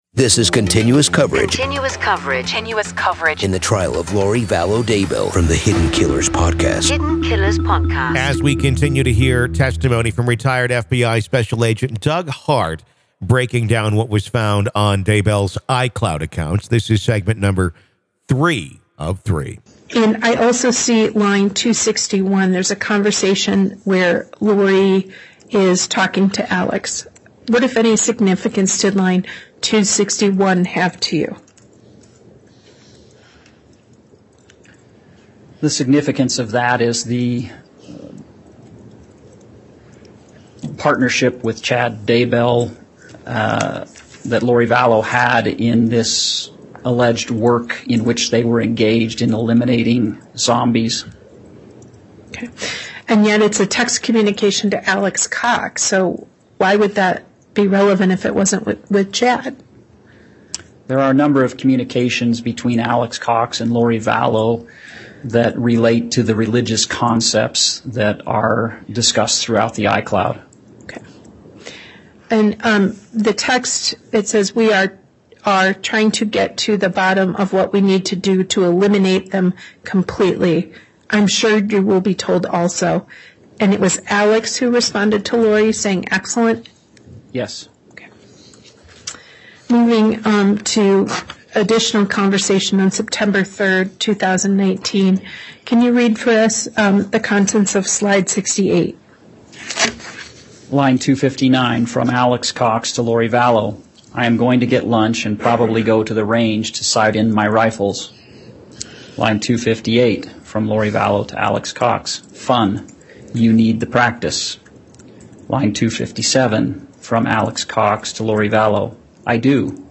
The Trial Of Lori Vallow Daybell | Full Courtroom Coverage